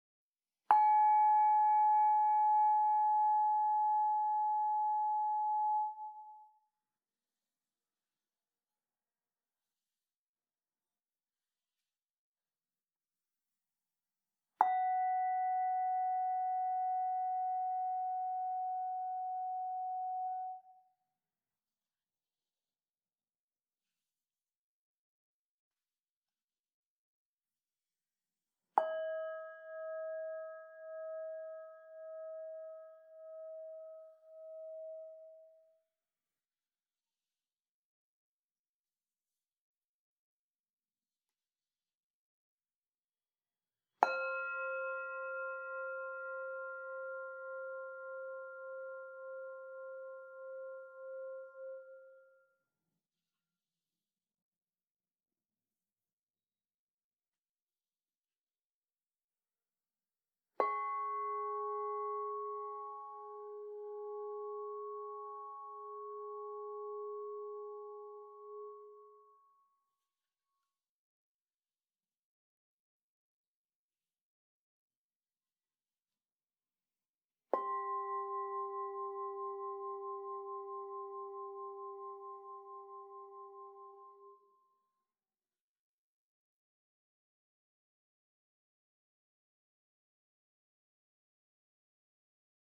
Meinl Sonic Energy 6-piece Essence Solfeggio Crystal Singing Bowl Set, Ut 396 Hz, Re 417 Hz, Mi 528 Hz, Fa 639 Hz, Sol 741 Hz, La 852 Hz (ESOLCSBSET)